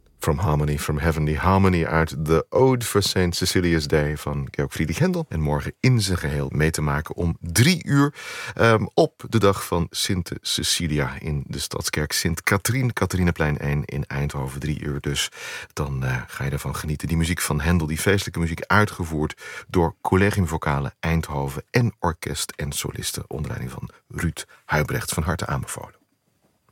Aankondiging van dit concert